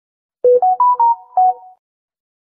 Nada notifikasi Pesan Samsung
Keterangan: Nada notif Pesan Samsung Galaxy yang sering dipakai...
nada-notifikasi-pesan-samsung-id-www_tiengdong_com.mp3